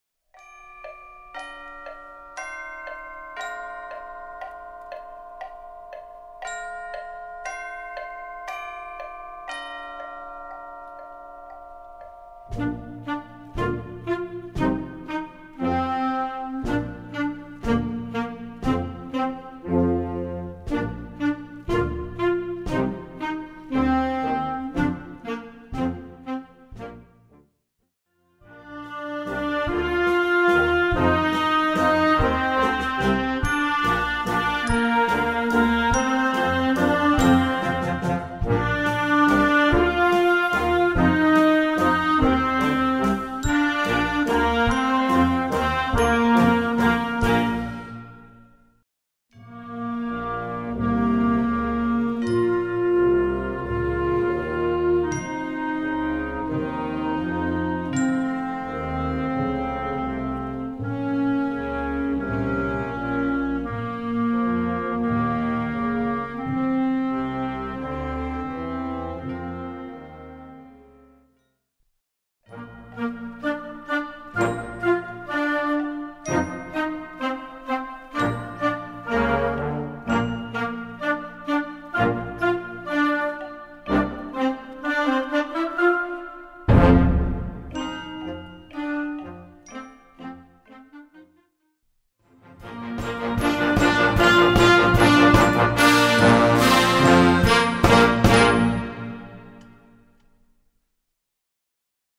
Besetzung: Blasorchester
Das Schlagzeug (Wood Blocks) schlägt den Sekunden-Rhythmus.